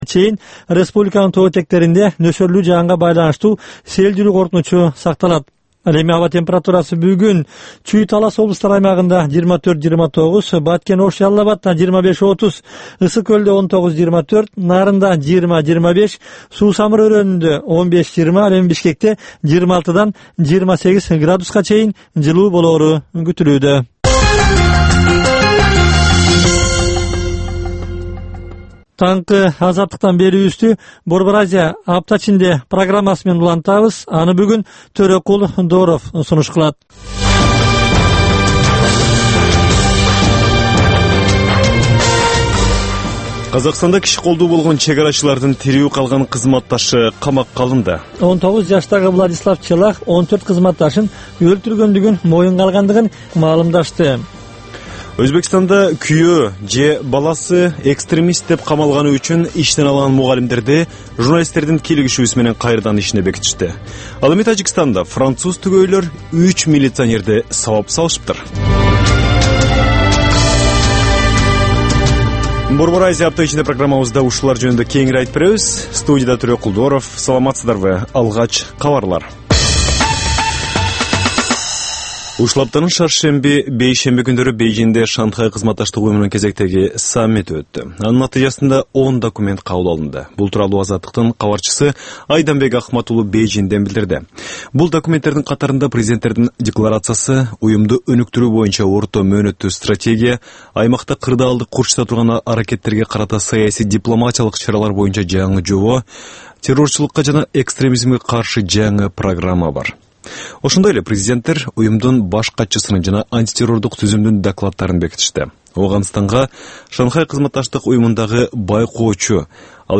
Бул таңкы үналгы берүү жергиликтүү жана эл аралык кабарлар, ар кыл орчун окуялар тууралуу репортаж, маек, күндөлүк басма сөзгө баяндама, «Арай көз чарай» түрмөгүнүн алкагындагы тегерек үстөл баарлашуусу, талкуу, аналитикалык баян, сереп жана башка берүүлөрдөн турат.